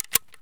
ammo_load2.ogg